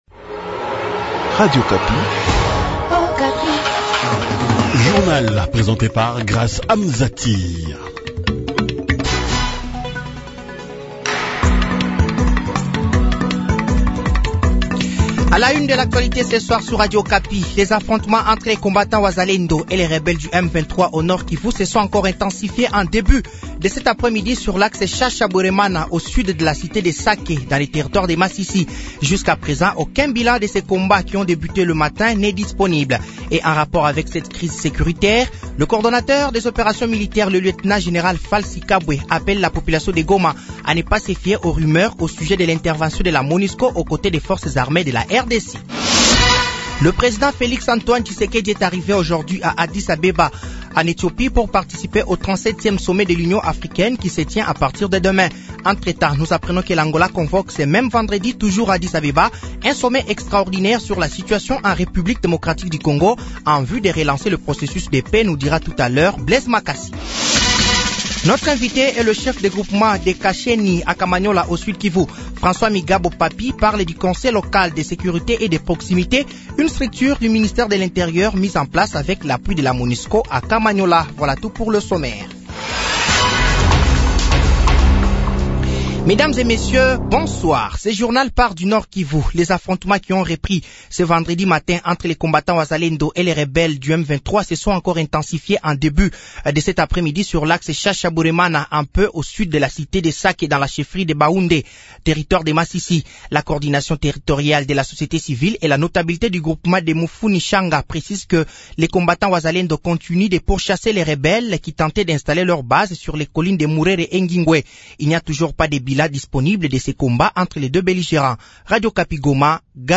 Journal français de 18h de ce vendredi 16 février 2024